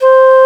20FLUTE01 -L.wav